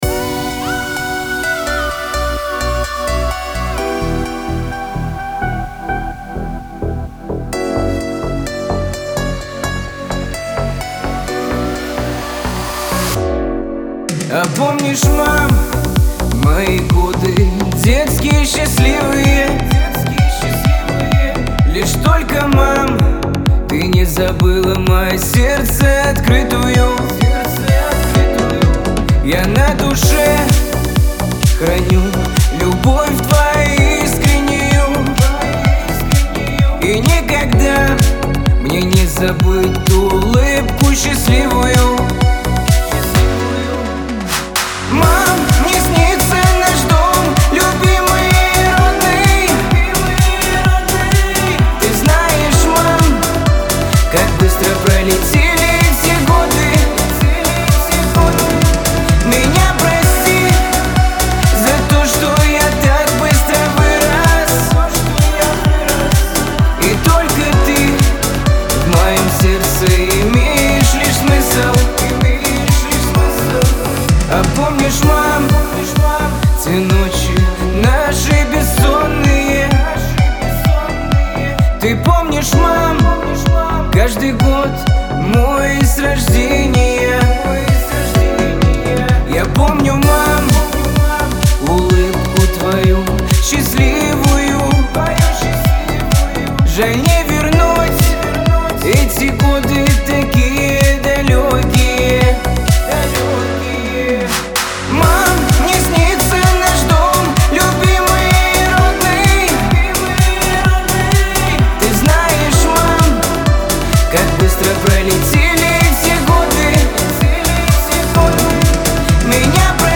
эстрада
pop